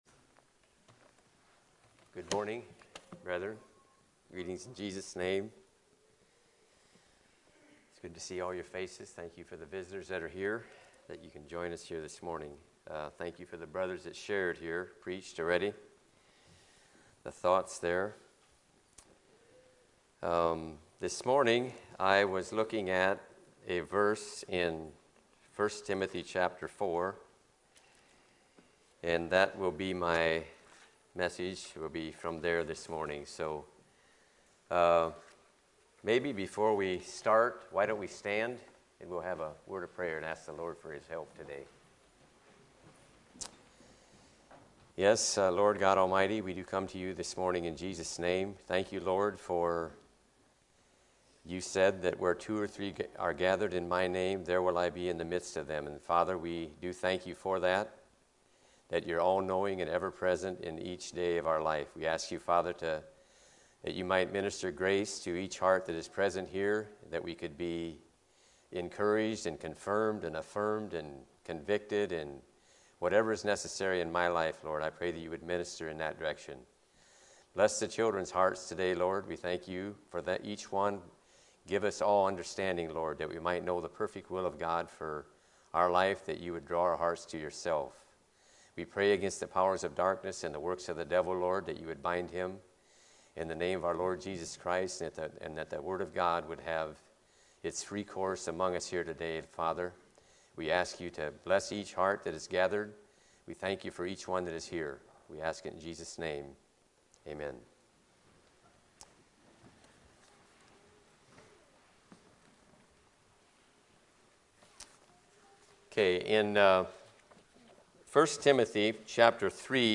Sunday Morning Sermons